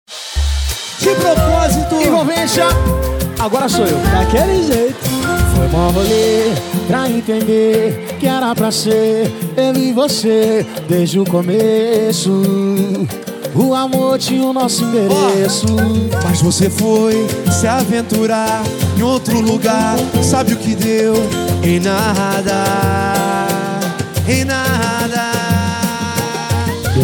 Isso mesmo, tem pagode novo chegando muito em breve!